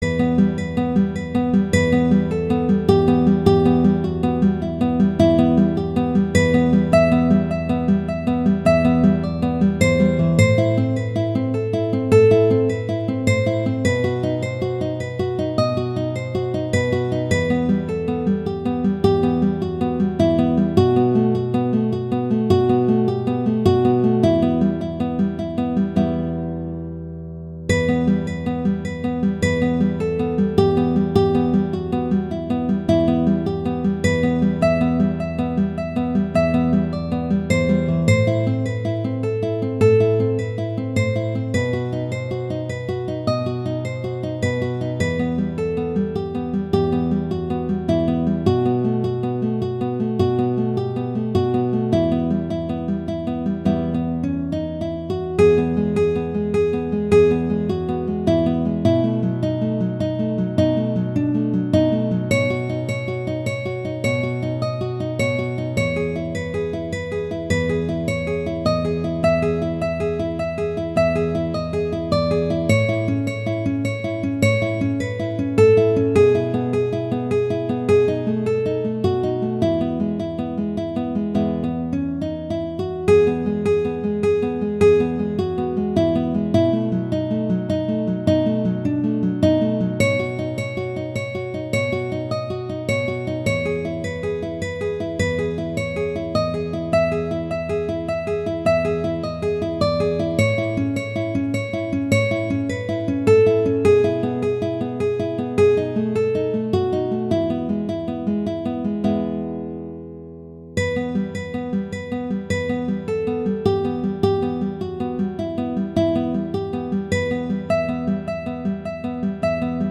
Instrumentation: guitar solo
Note Range: E2-E5
classical
E minor
♩=104 BPM